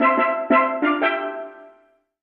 Music Logo; Stereotypical Steel Drum.